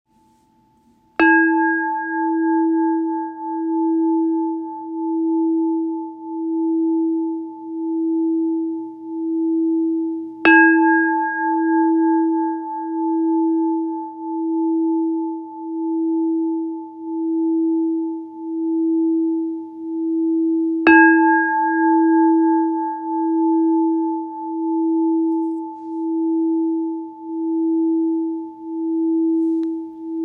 Zpívající křišťálová mísa
Křišťálová mísa s měsíčním kamenem Duch VELRYBY - tón E, 3. čakra solar plexus
432 Hz
Křišťálová mísa alchymická - tón E